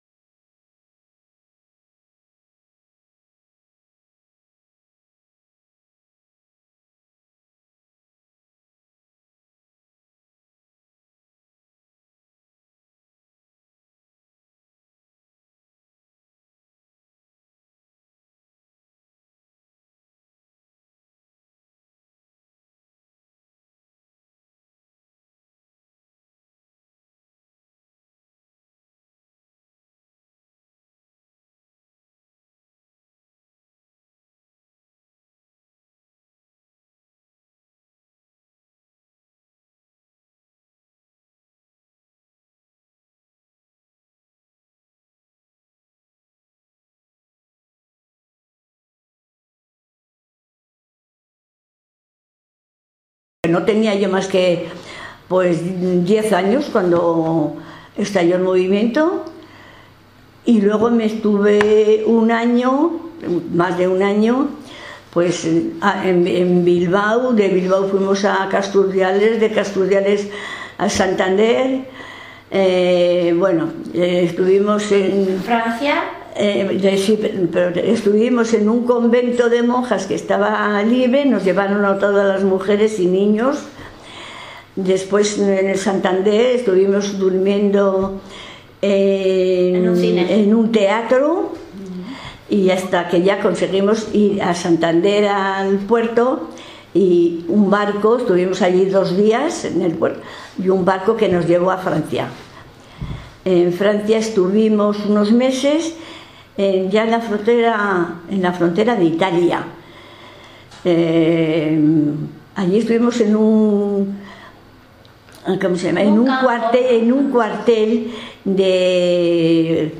Interviews with women and men who lived the first years of the Franco regime.